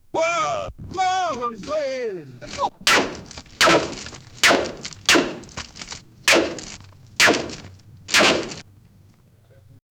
Pickup a soda can 0:10 5 men in a struggle on pavement. they are fighting with each other, grunts and scuffling of the feet and one of them is hitting the other with a baseball bat made of aluminum. 0:10
5-men-in-a-struggle-mxsy27qe.wav